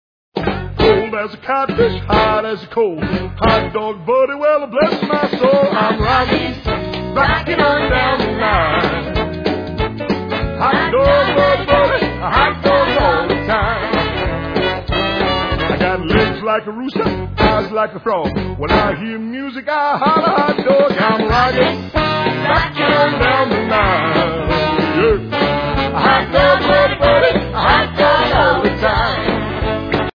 From bouncy and boisterous to warm and furry